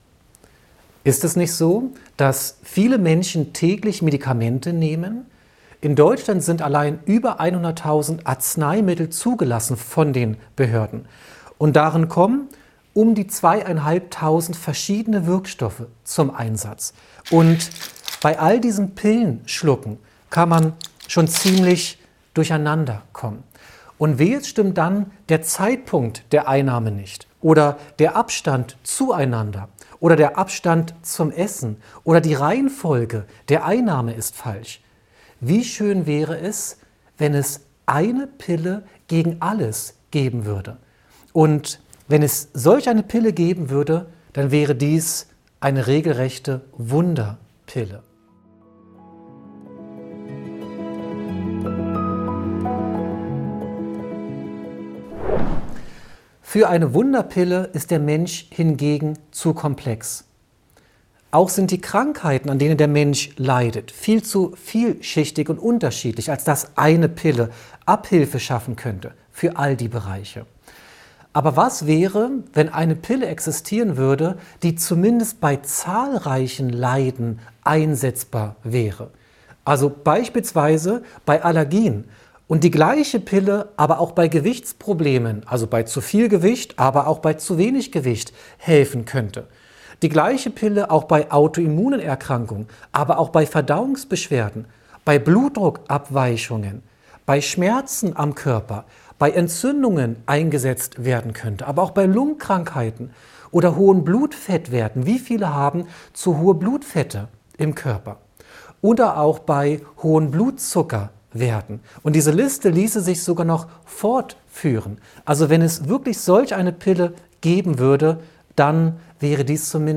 In diesem faszinierenden Vortrag werden die Möglichkeiten des Schwarzkümmelöls als natürlichem Heilmittel beleuchtet. Dieses vielseitige Pflanzenprodukt könnte eine Vielzahl von Erkrankungen behandeln, vom Gewicht über Allergien bis hin zu Autoimmunerkrankungen.